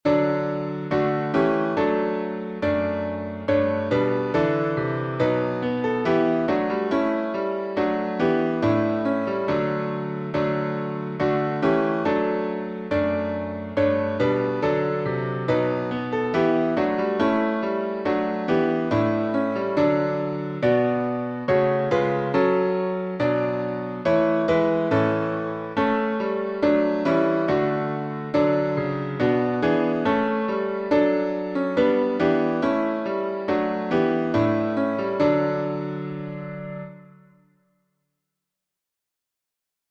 #6031: Sing Praise to God Who Reigns Above — D major | Mobile Hymns